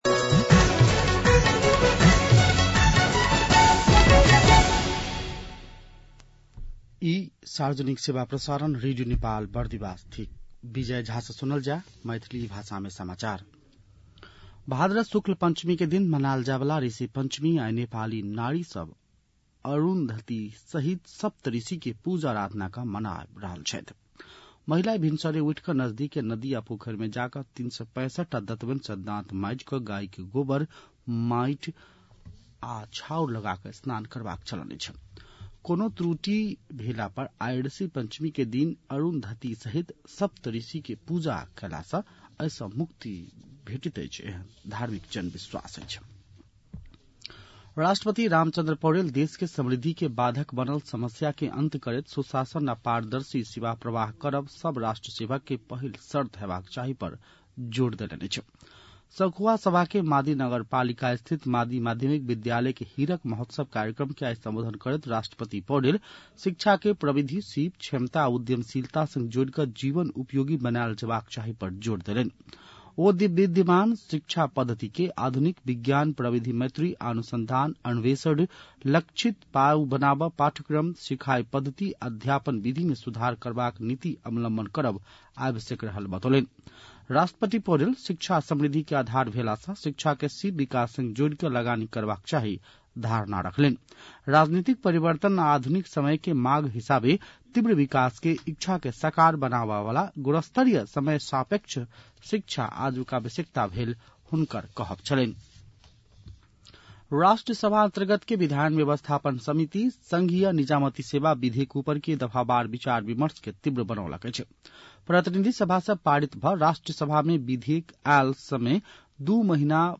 मैथिली भाषामा समाचार : १२ भदौ , २०८२